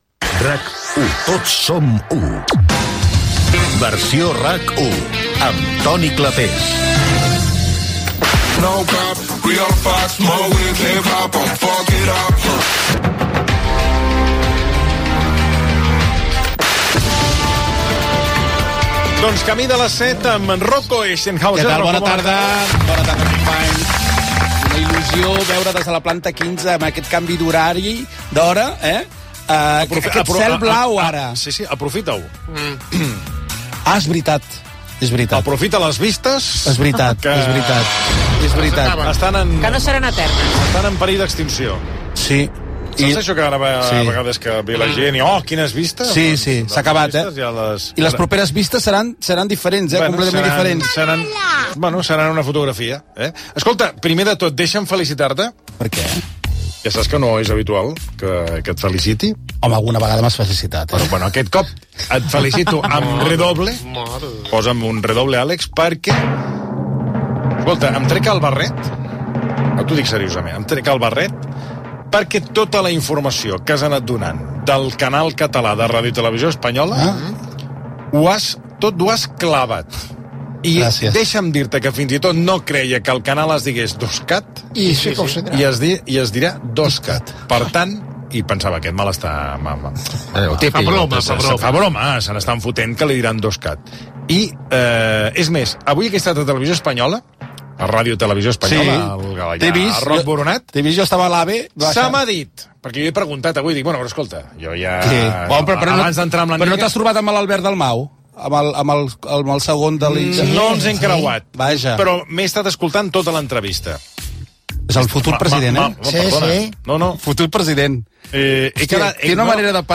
Indicatiu del programa, secció dedicada a l'actualitat televisiva, el nou canal de RTVE a Catalunya 2Cat (amb declaracions del conseller de la Presidència, Albert Dalmau)
Gènere radiofònic Entreteniment
Banda FM